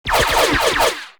Phaser blast sound effect